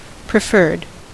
Ääntäminen
IPA: [fa.vɔ.ʁi]